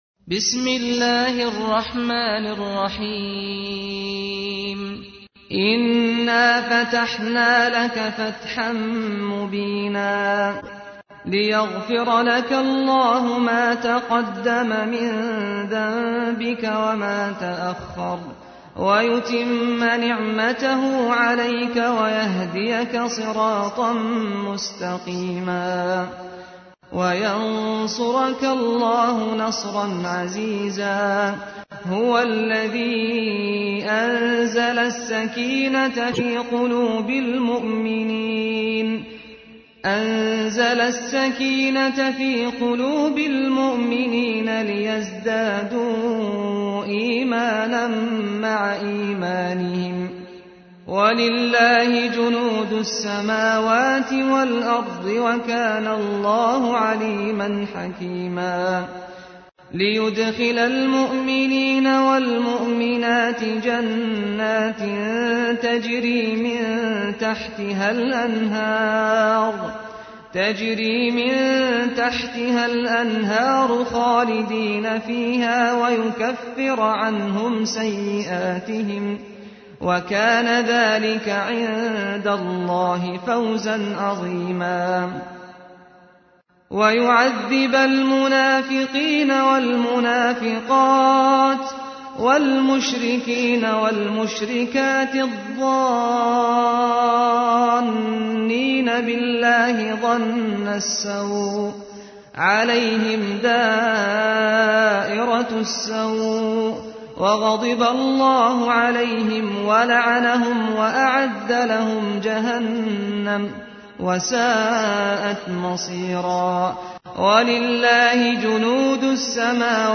تحميل : 48. سورة الفتح / القارئ سعد الغامدي / القرآن الكريم / موقع يا حسين